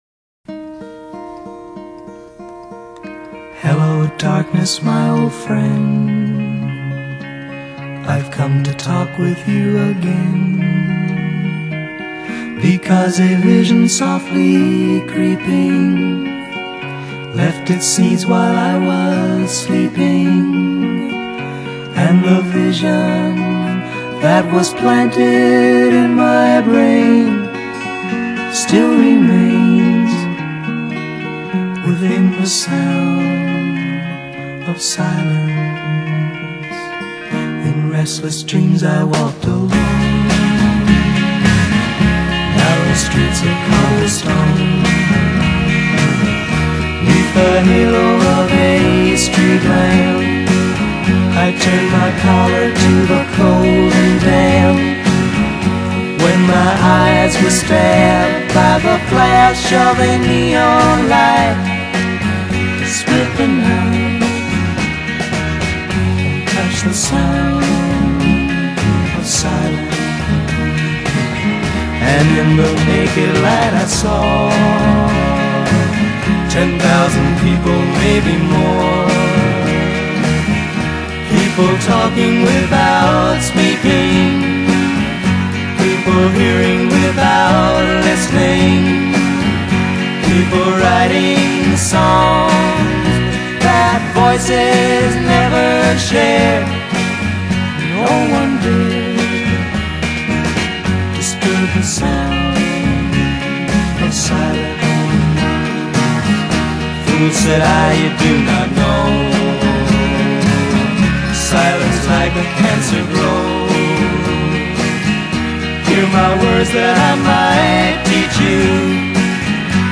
Genre: Pop Rock, Folk